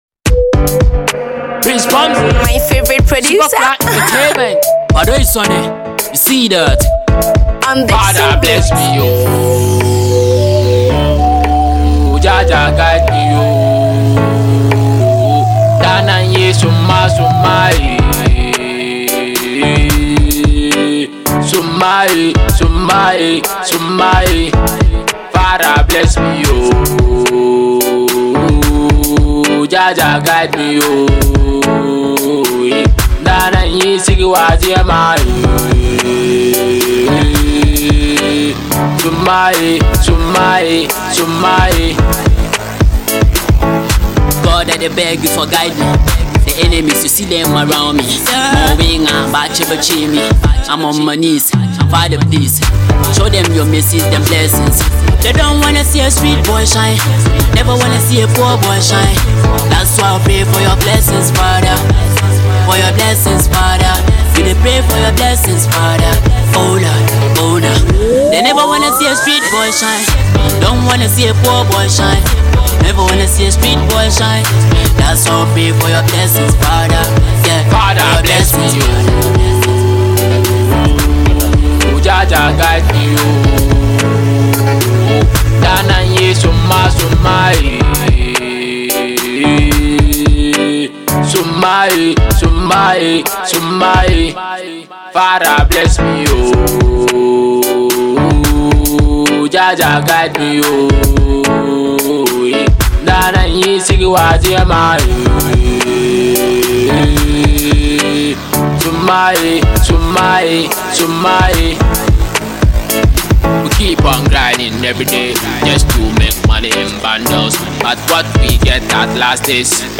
rap
This is a massive tune you should never miss.